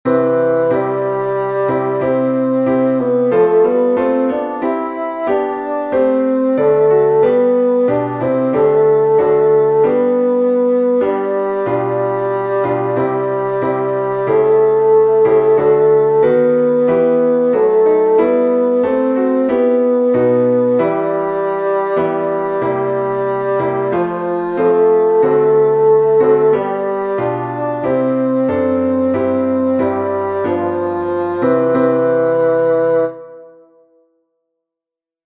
Tenor
eternal_father-pd-tenor.mp3